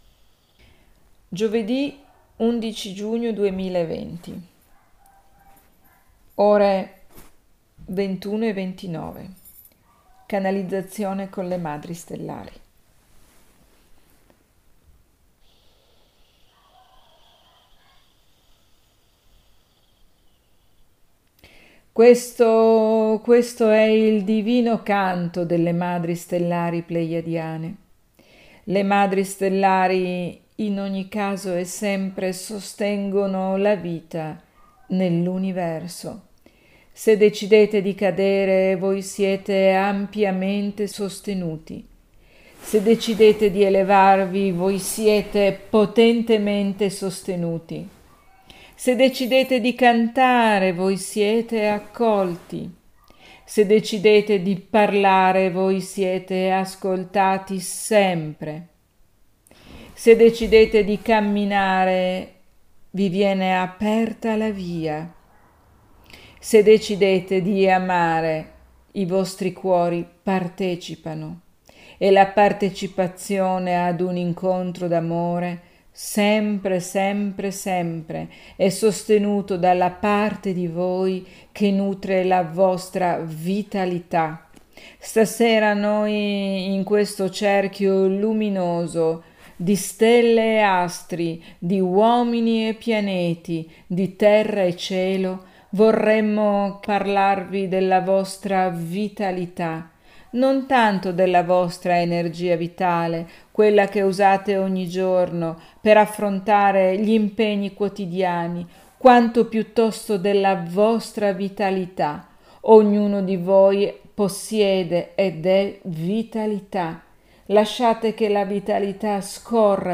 Gioved� 11 giugno 2020 nel webinar del gruppo fb Ponti di Luce